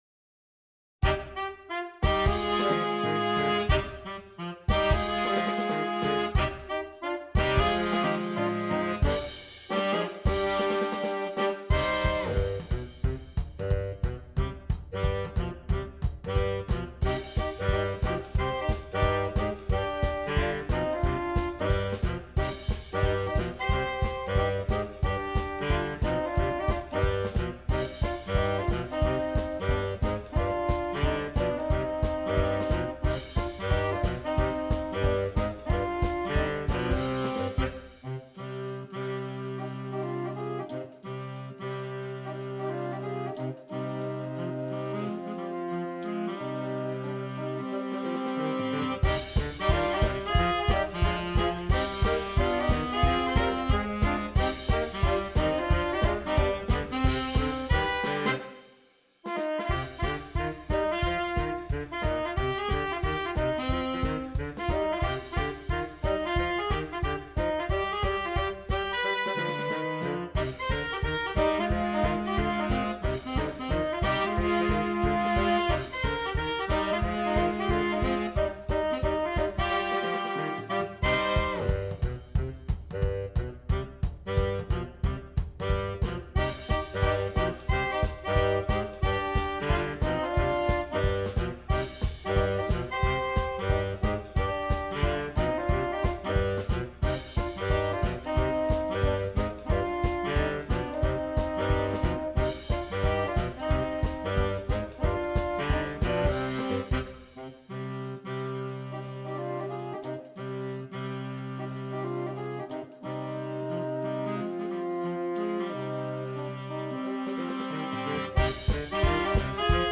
Saxophone Quartet (AATB) Optional Drums